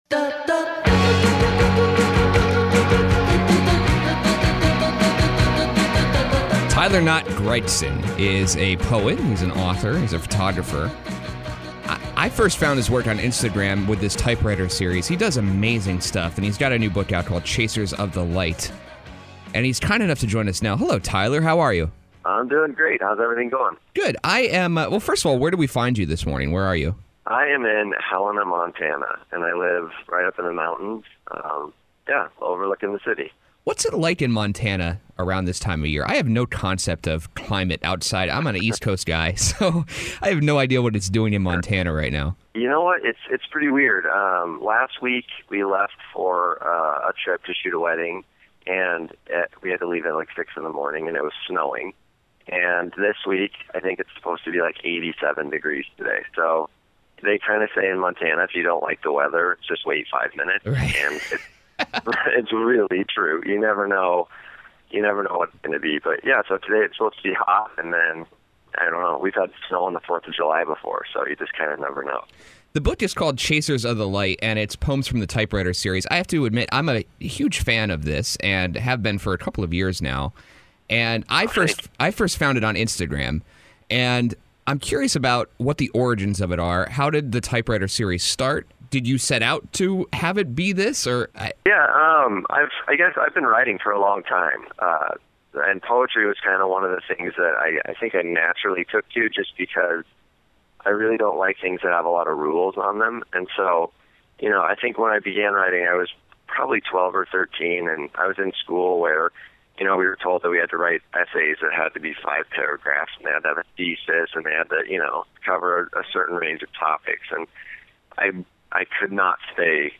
Interview: Tyler Knott Gregson